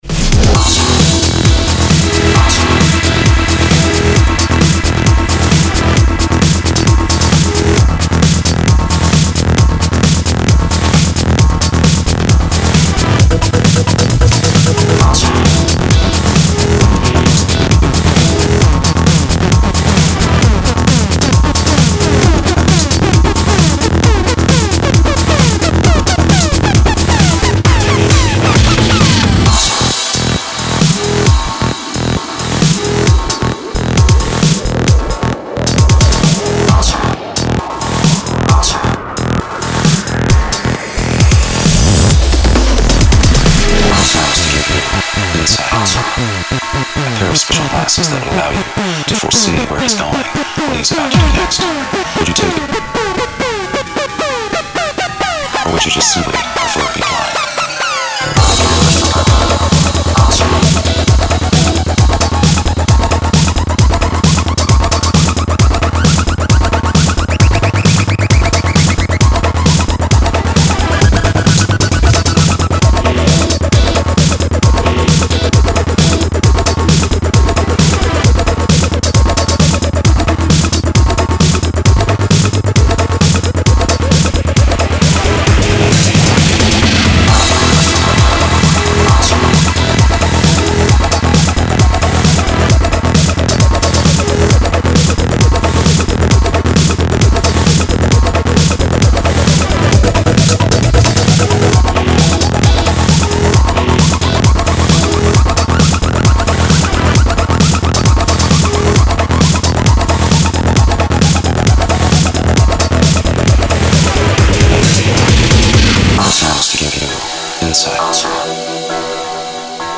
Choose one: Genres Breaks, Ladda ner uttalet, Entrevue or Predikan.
Genres Breaks